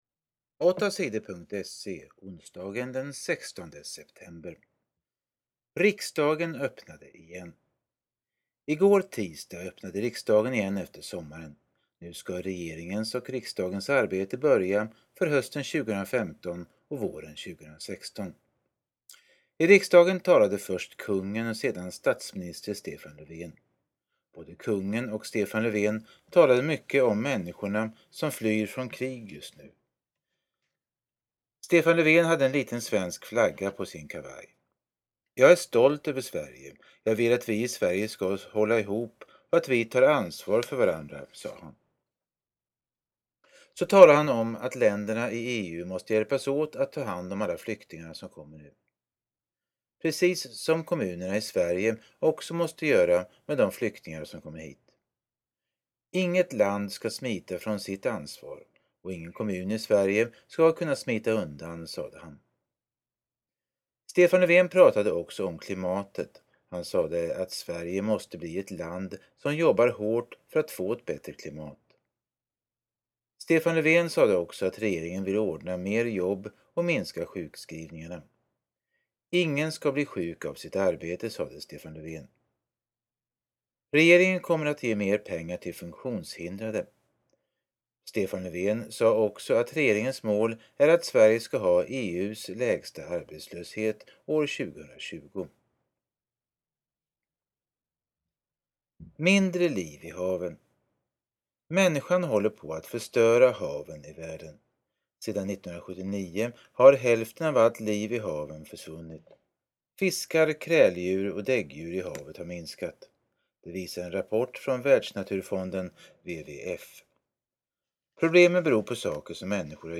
Lyssna på nyheterna 16 september